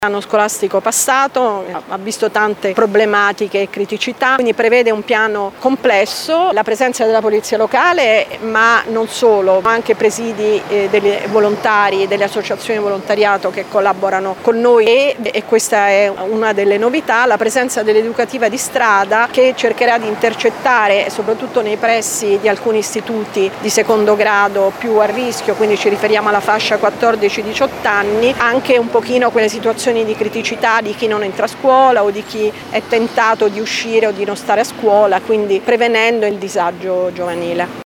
Il piano prevede dunque pattugliamenti mobili nelle aree di assembramento scolastico ma anche incontri informativi con gli studenti nell’ambito della cosiddetta “educativa di strada”. L’assessore alla sicurezza Alessandra Camporota: